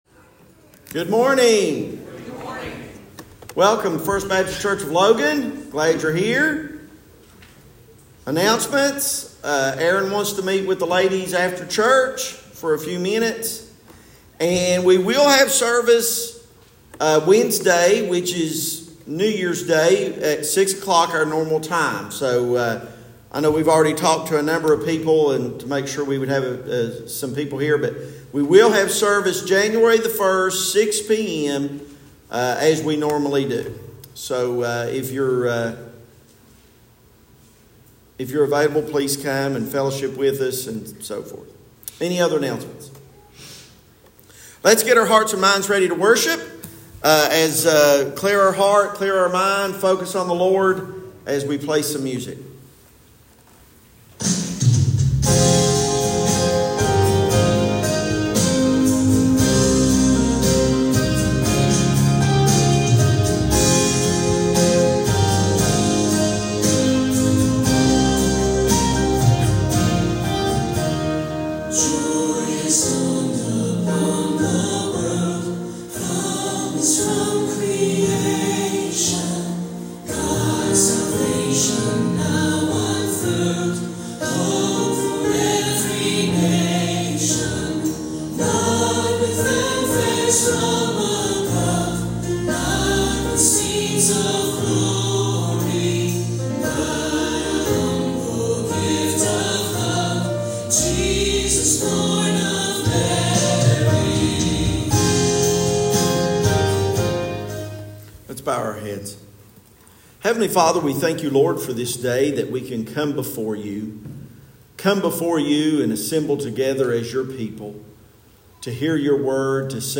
Sermons | First Baptist Church of Logan